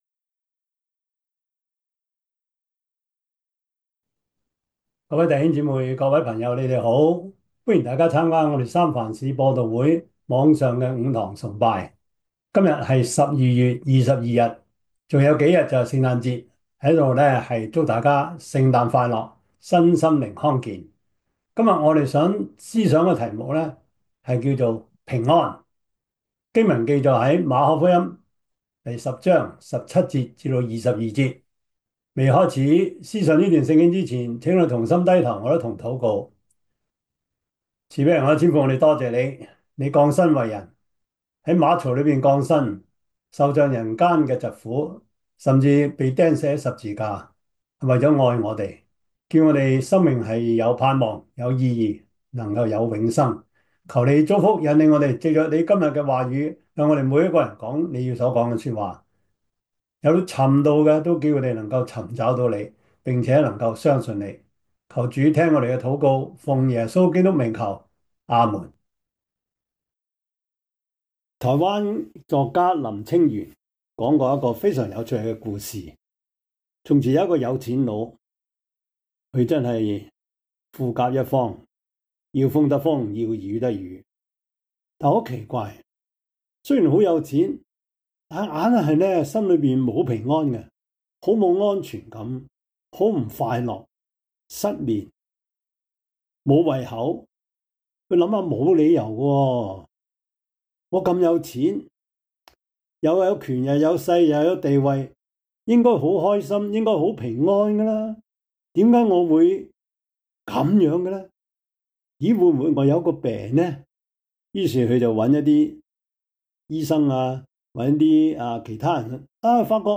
Service Type: 主日崇拜
Topics: 主日證道 « 這位就是和平 認識精神病及情緒病 – 15 »